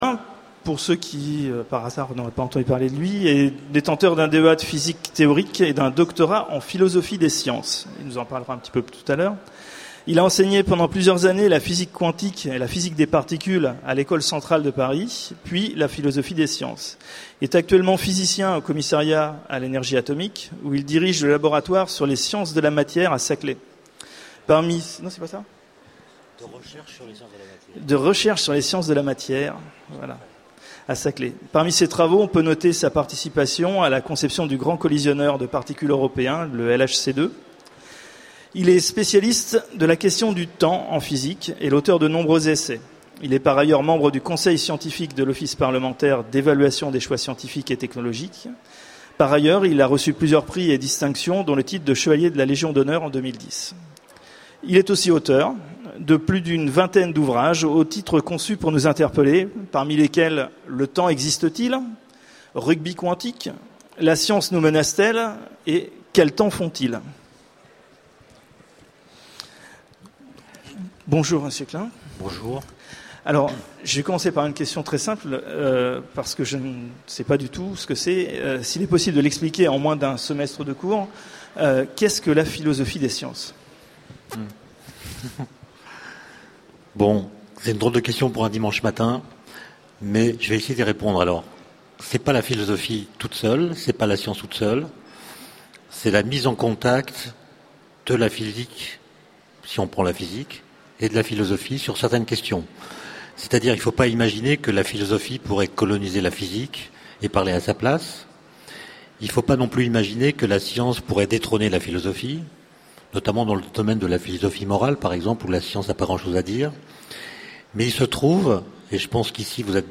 Utopiales 12 : Conférence Rencontre avec Étienne Klein
Conférence